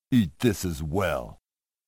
If you pull the switch, you even get a voice clip: